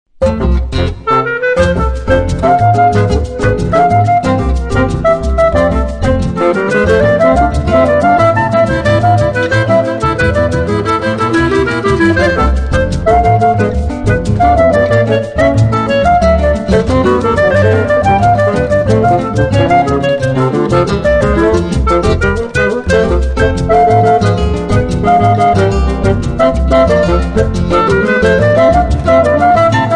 fagotto
clarinetto
chitarra
contrabbasso
percussioni